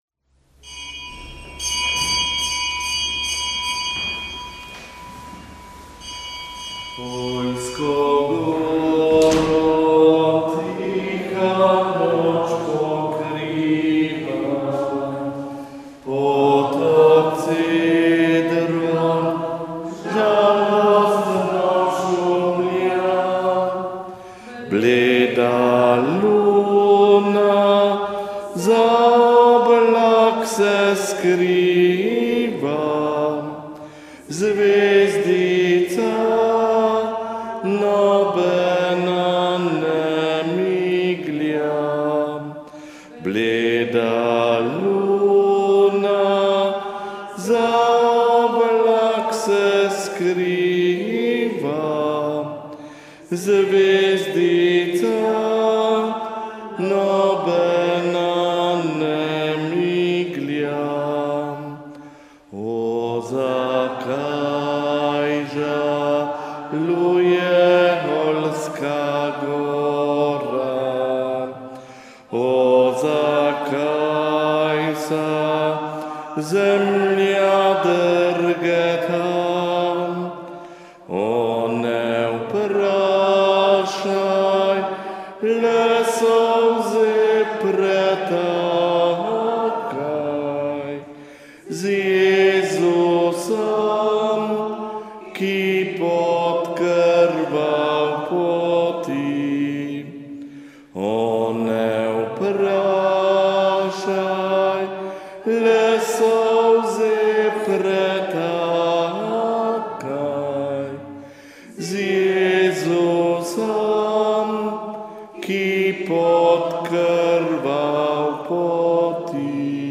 Sveta maša
Prenos konventne maše iz fračiškanske cerkve Marijinega oznanjenja v Ljubljani
Konventna maša iz frančiškanske cerkve Marijinega oznanjenja v Ljubljani in molitev za zdravje sester Uršulink.